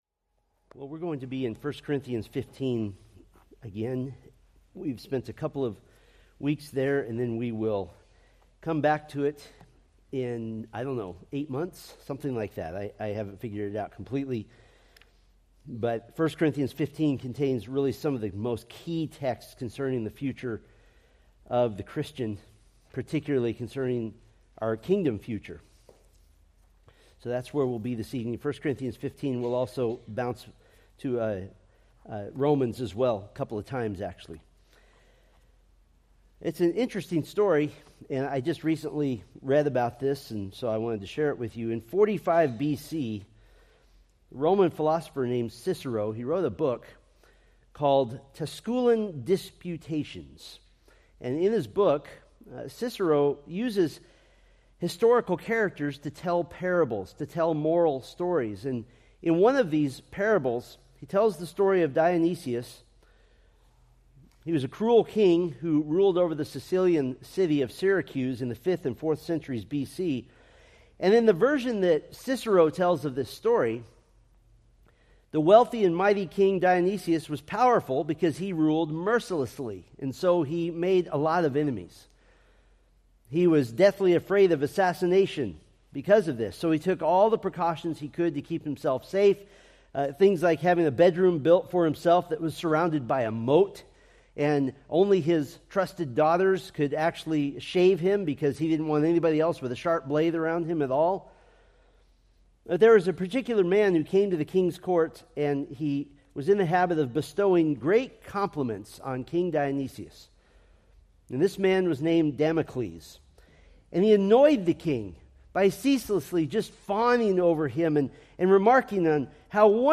Preached December 15, 2024 from 1 Corinthians 15